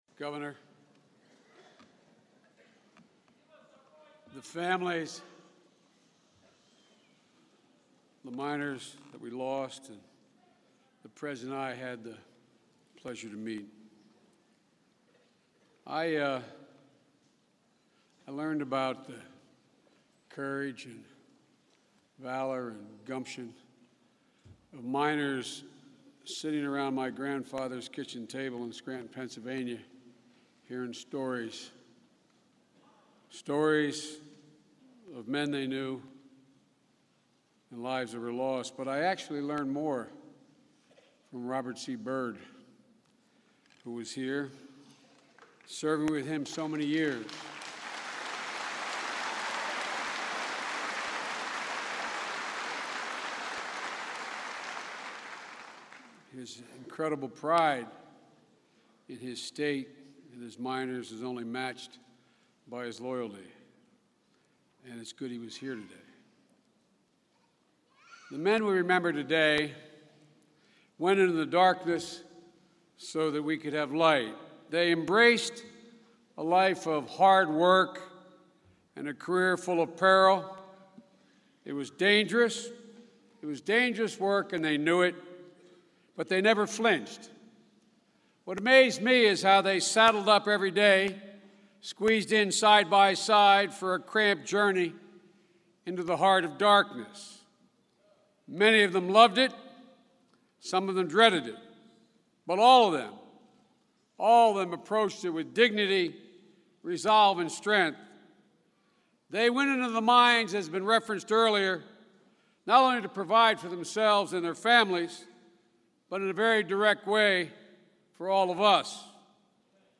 U.S. President Barack Obama and Vice President Joe Biden speak at the memorial service for victims in the Upper Big Branch Mine explosion